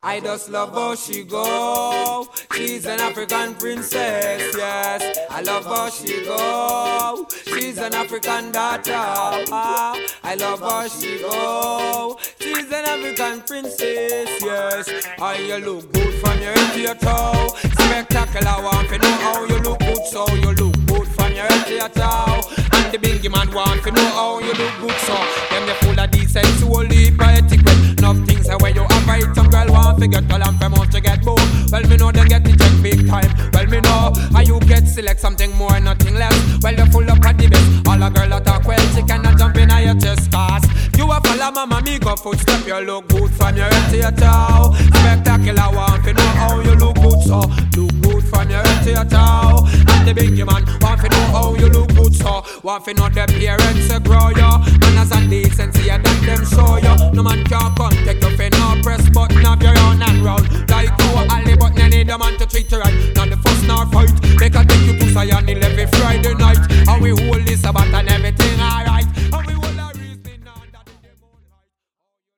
a hiphop flavoured Ragga track